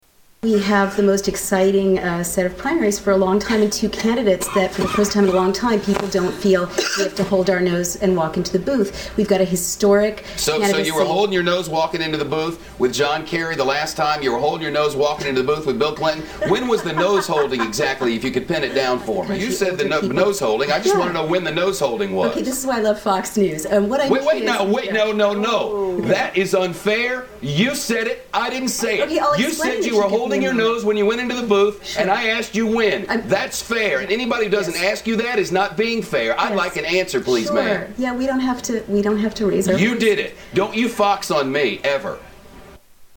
Goes off on Naomi Wolf
Tags: Media Shepard Smith News Anchor Shepard Smith The Fox Report News Anchor